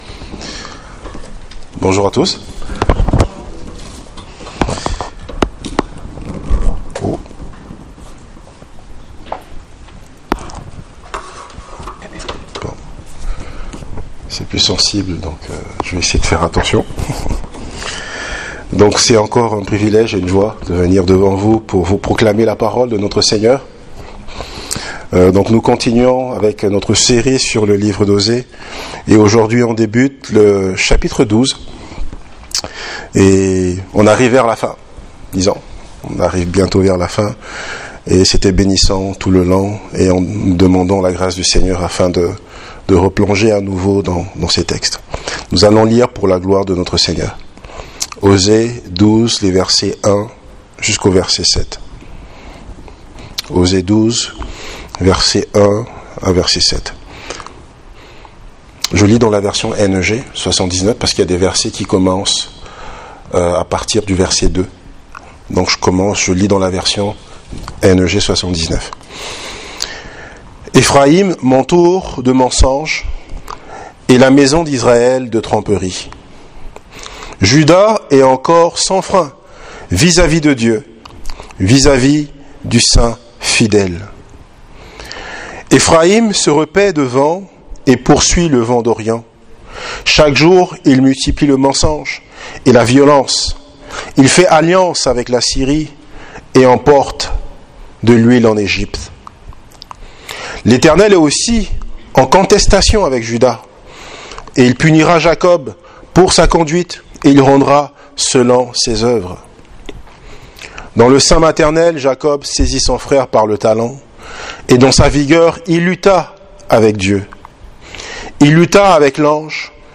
Serie de sermons